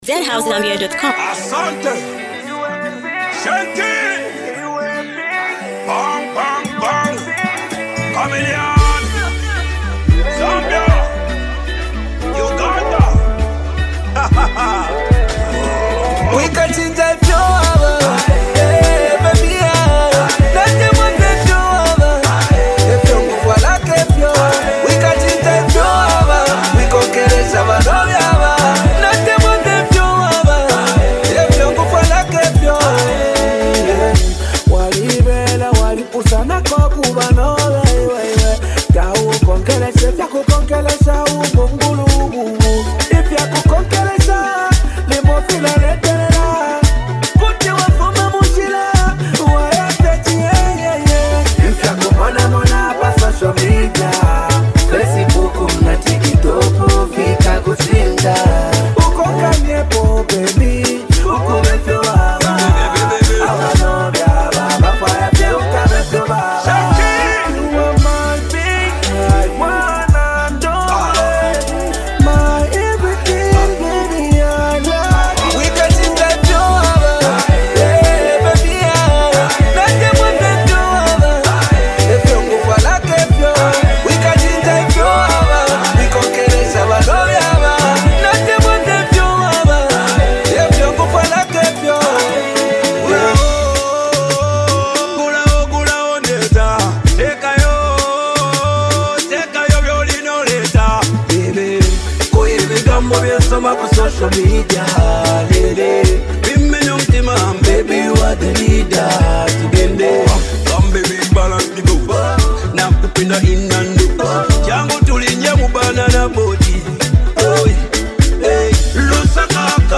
a fusion of Afrobeat and dancehall vibes!